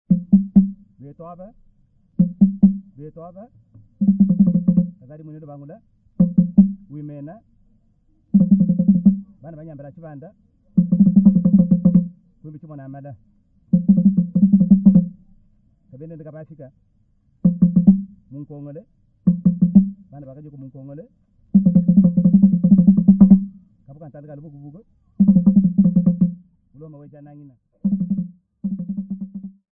HTFT523-L145-L3D5b.mp3 of Drum greeting